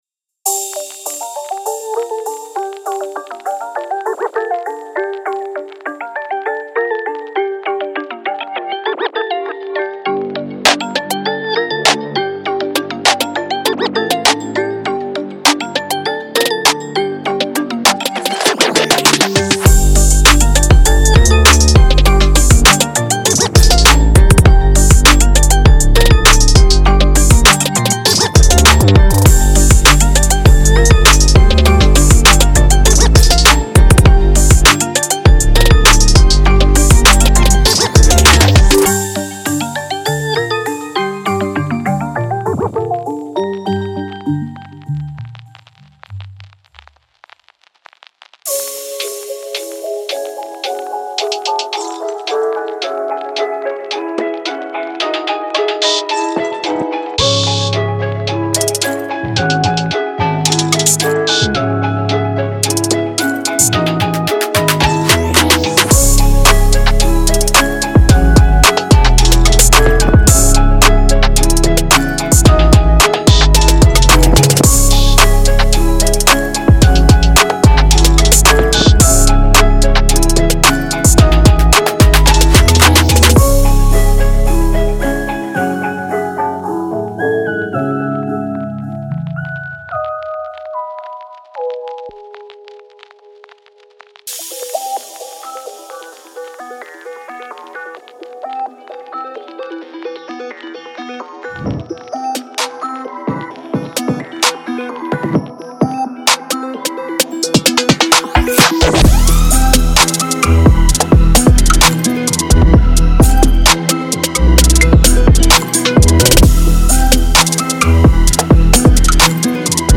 Hip HopTrap
整个音高，叮当响和响声都像这个超级促销介绍中所期望的那样微调，实际上并没有。